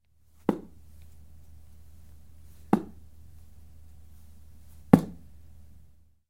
Звуки дартса
Звук вонзающихся дротиков в мишень